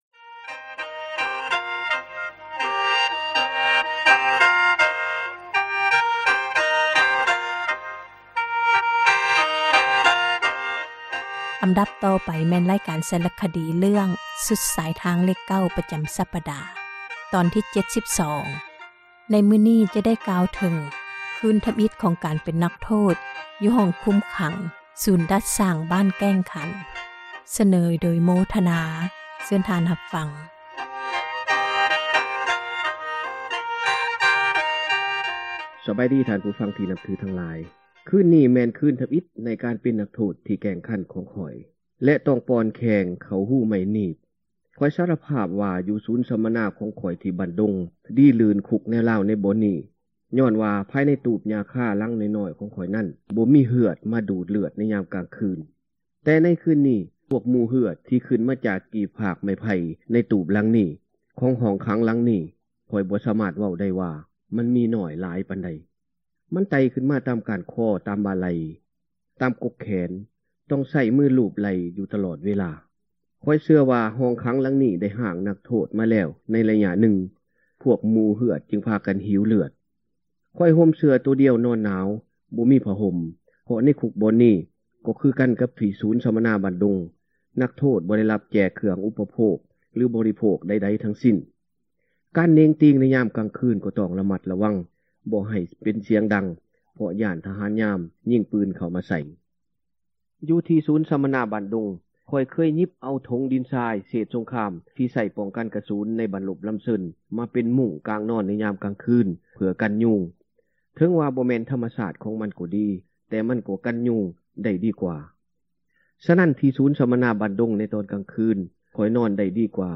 ສາຣະຄະດີ ເຣື້ອງ ສຸດສາຍທາງເລຂ 9 ຕອນທີ 72 ຈະກ່າວເຖິງ ຄືນທໍາອິດ ຂອງການເປັນນັກໂທດ ໃນຄຸກຄຸມຂັງ “ສູນດັດສ້າງ ບ້ານແກ້ງຂັນ.”